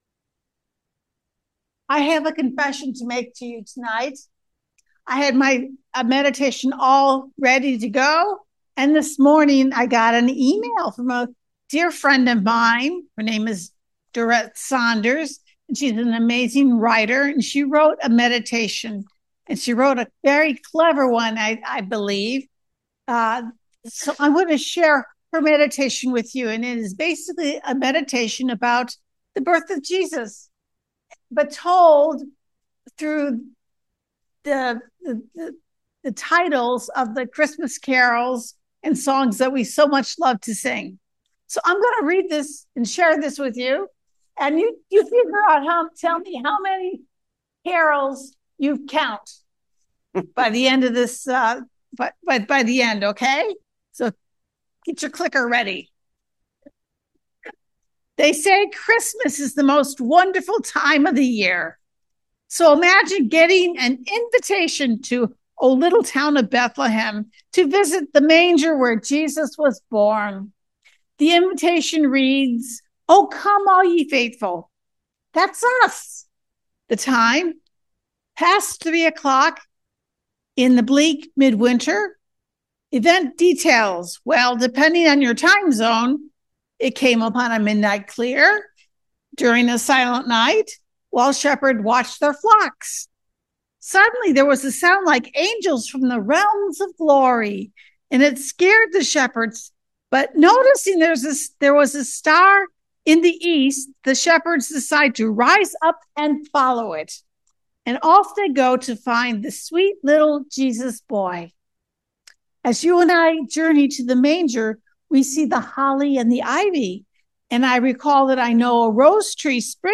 Christmas Eve